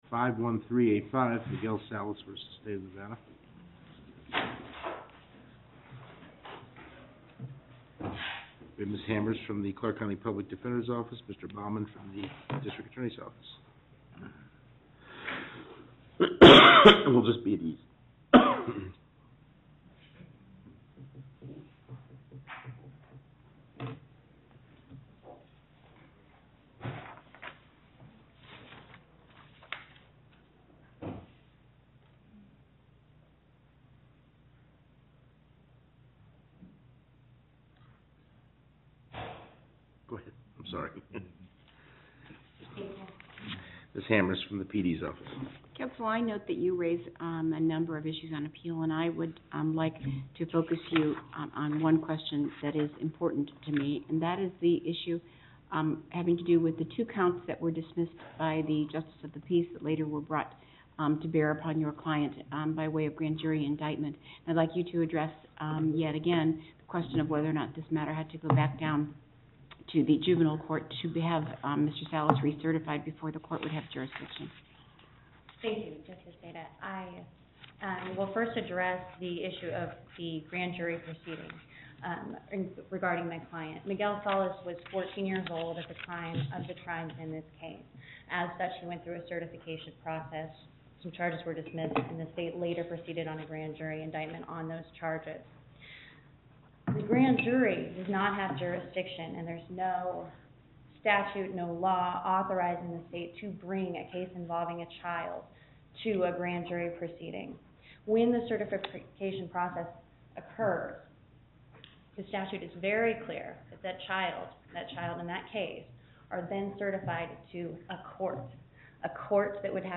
Location: Las Vegas
Justice Cherry Presiding